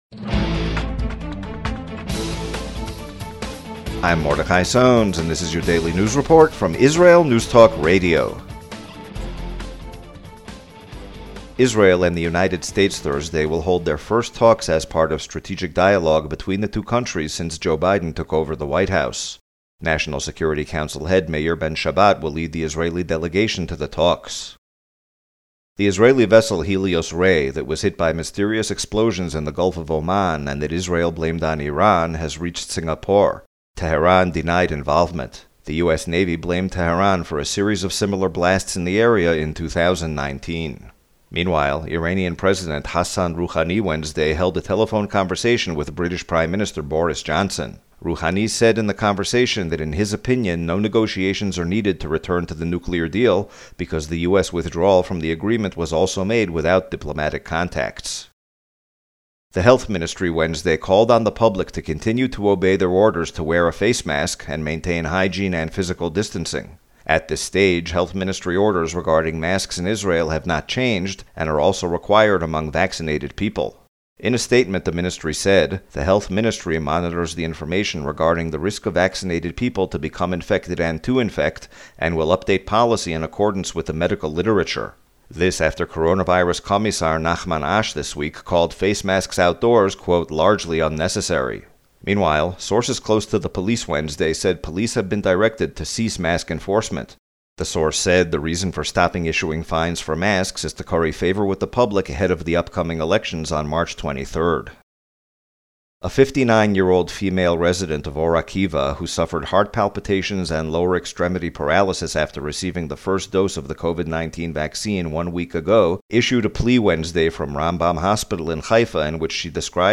radio-news-report-about-pfizer-vaccinations.mp3